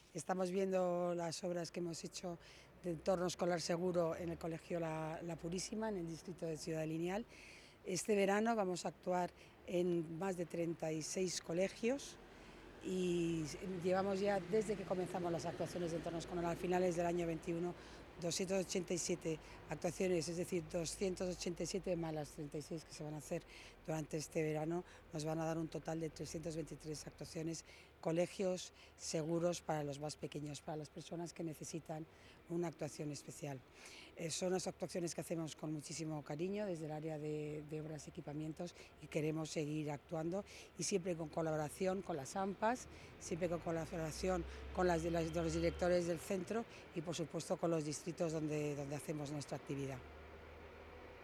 Así lo ha explicado la delegada de Obras y Equipamientos, Paloma García Romero, durante la visita que ha realizado al entorno del colegio La Purísima, en el distrito de Ciudad Lineal, que ha sido recientemente objeto de este tipo de trabajos.
DECLARACIONES-PALOMA-GARCIA-ROMERO-ENTORNO-ESCOLAR-SEGURO.wav